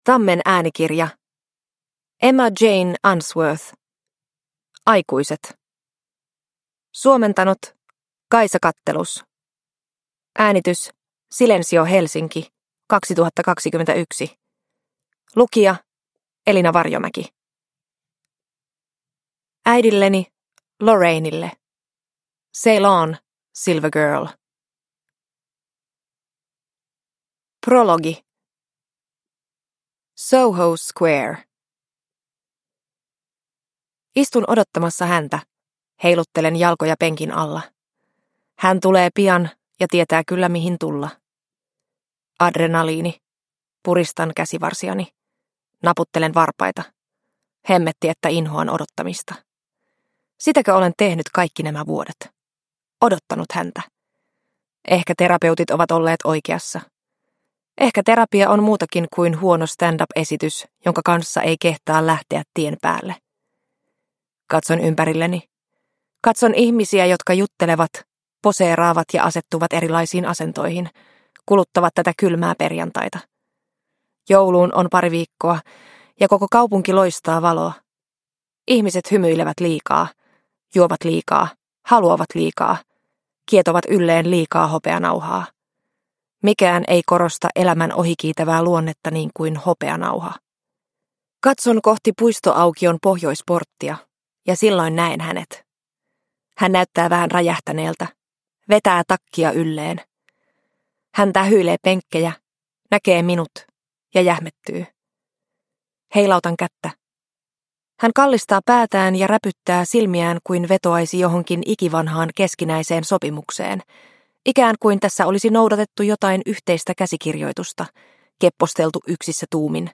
Aikuiset – Ljudbok – Laddas ner